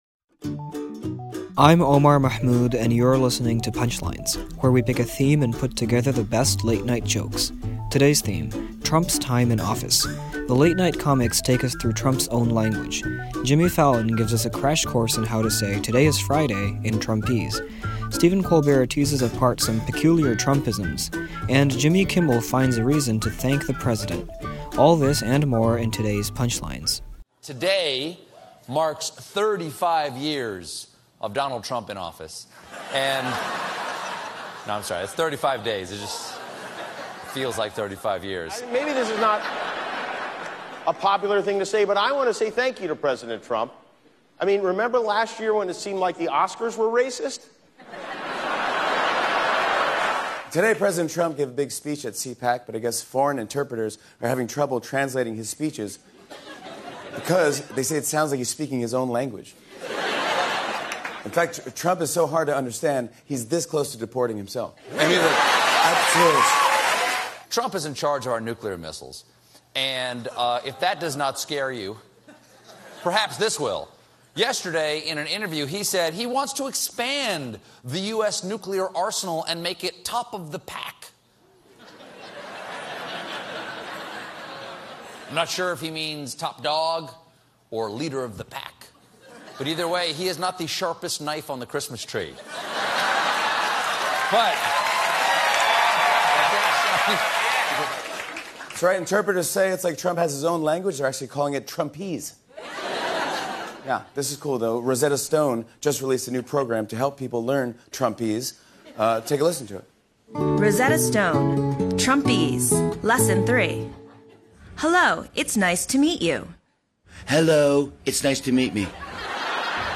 The late-night comics on changes under the new administration.